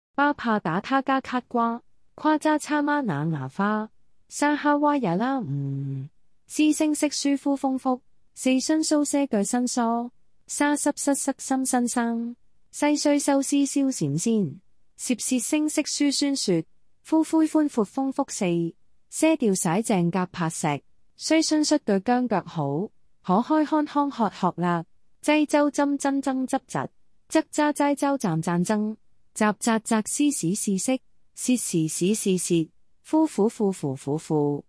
116字学会粤拼-ttsmaker-嘉欣.mp3